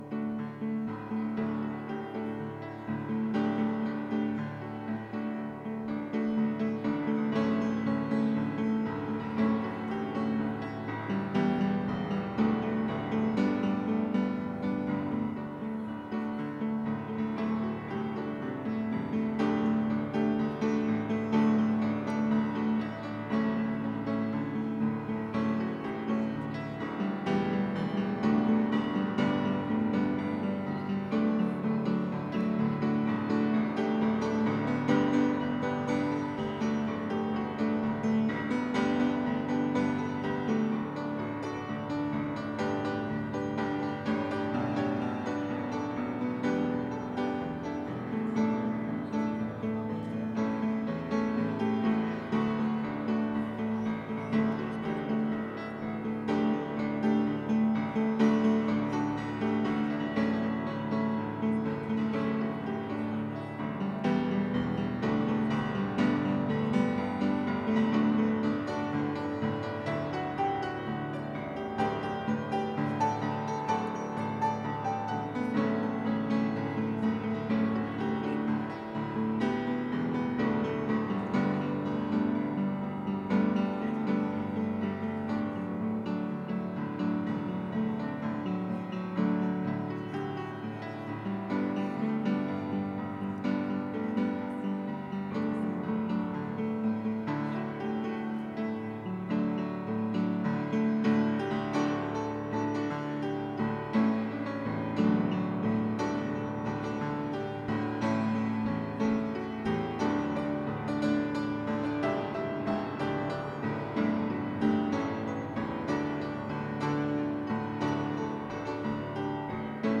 September 10, 2023 (Sunday Morning Service)